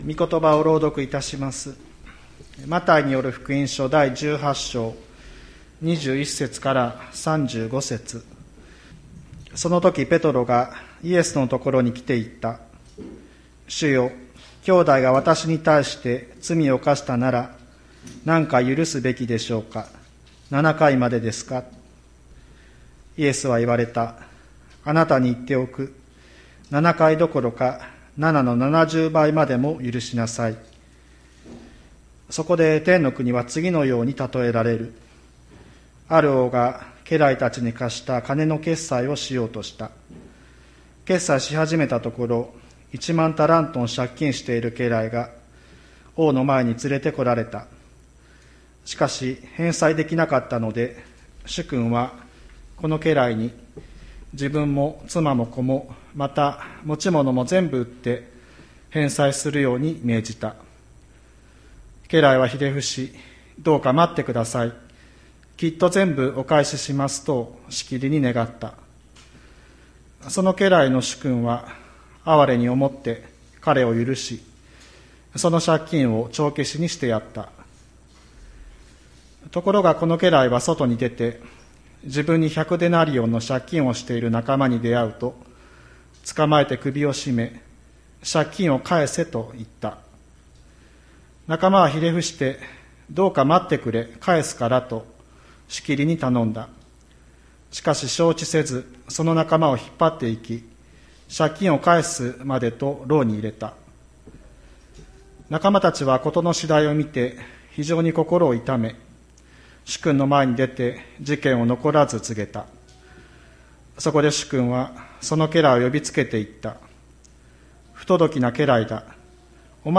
2022年03月20日朝の礼拝「もう一度、心から」吹田市千里山のキリスト教会
千里山教会 2022年03月20日の礼拝メッセージ。